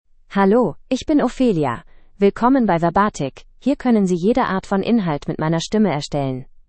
OpheliaFemale German AI voice
Ophelia is a female AI voice for German (Germany).
Voice sample
Listen to Ophelia's female German voice.
Female
Ophelia delivers clear pronunciation with authentic Germany German intonation, making your content sound professionally produced.